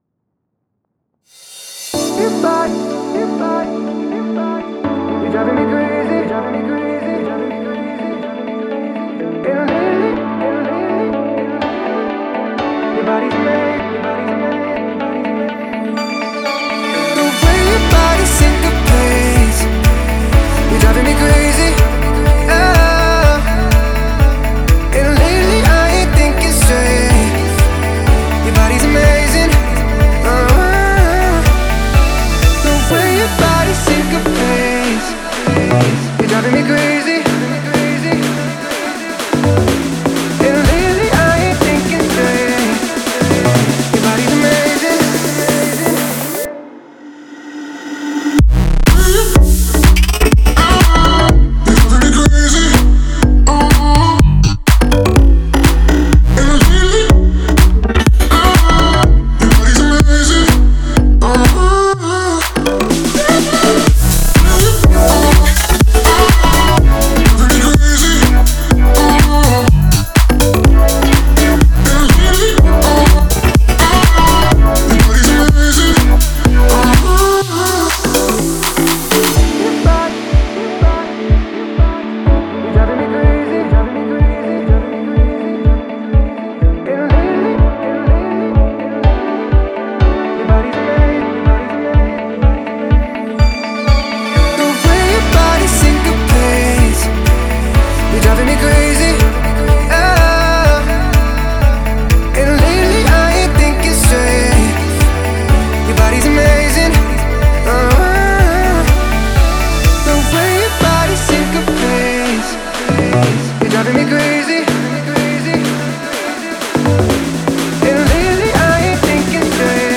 House, Epic, Dreamy, Energetic, Happy